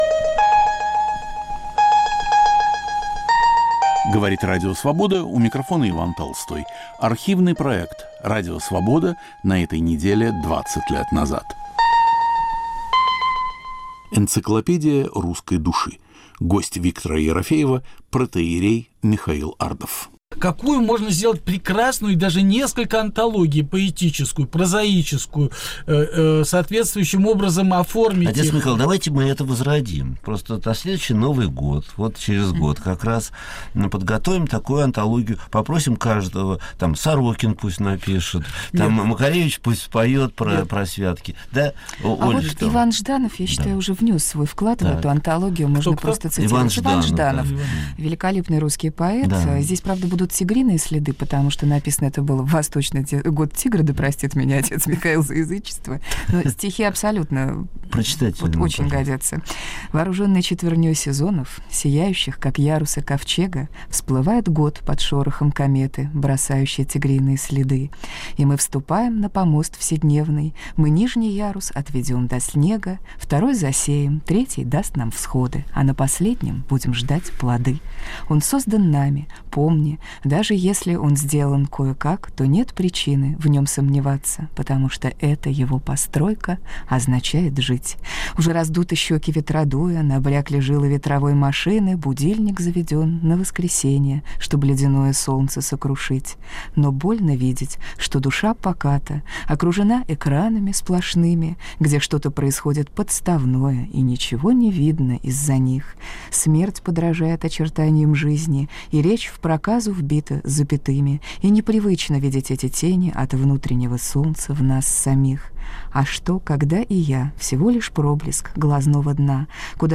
Русские святки: традиции старинные и новые. В студии